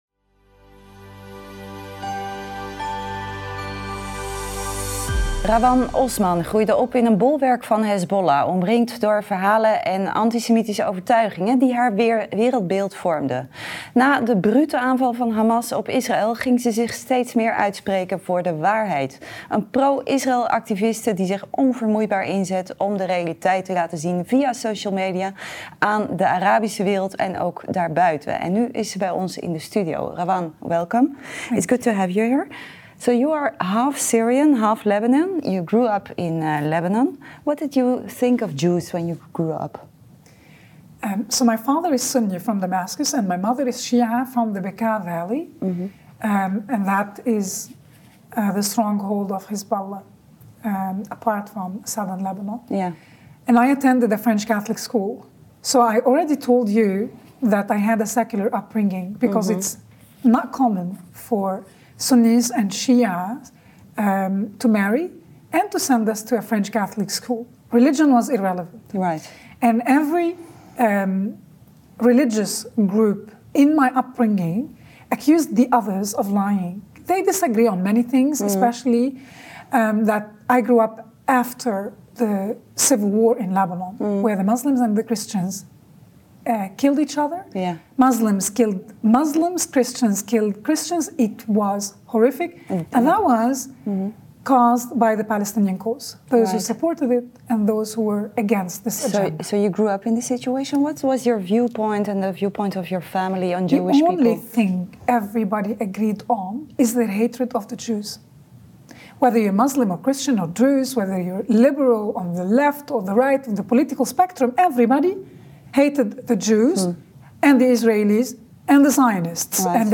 Bij ons in Nijkerk kon ze wel haar verhaal doen.